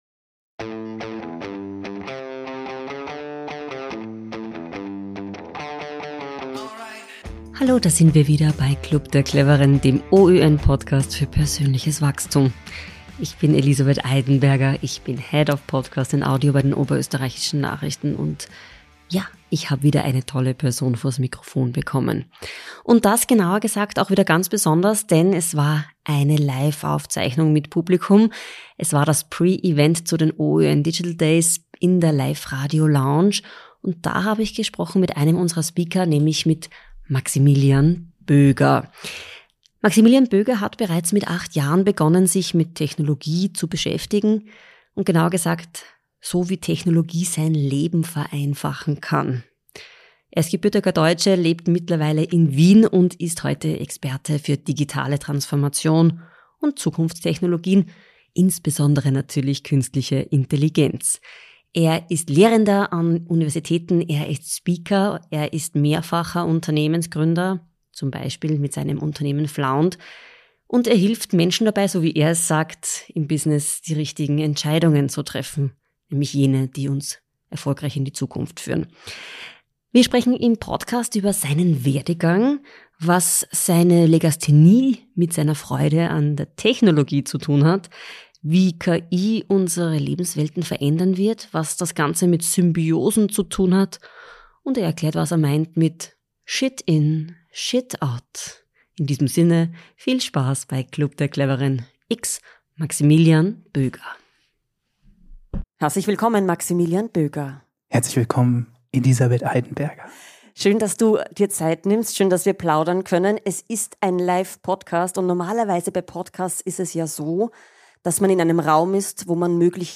Live) ~ Club der Cleveren Podcast